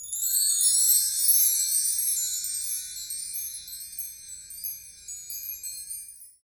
Index of /90_sSampleCDs/Roland LCDP03 Orchestral Perc/PRC_Wind Chimes2/PRC_Marktree